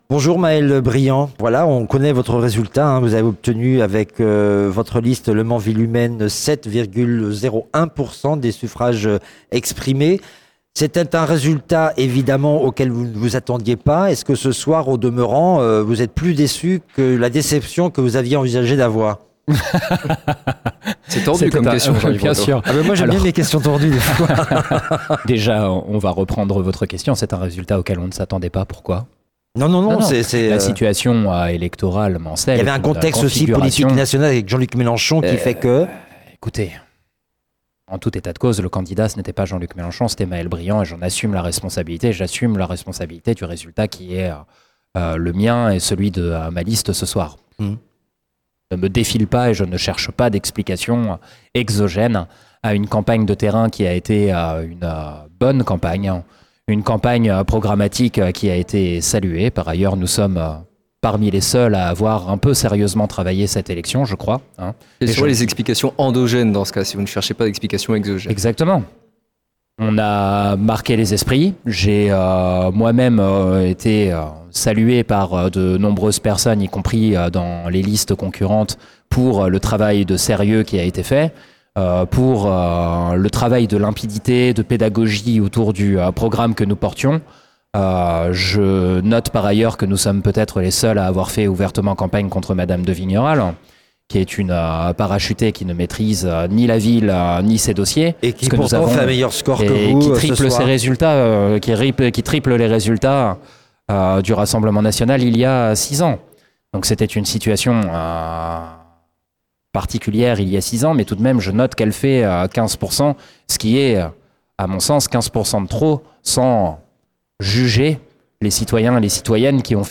présents à la Préfecture pour l’annonce des résultats du premier tour des élections municipales, au Mans et en Sarthe.
De 19h45 à 22h20, ils ont reçu plusieurs élus du territoire et la plupart des têtes de liste du Mans pour commenter et analyser les résultats.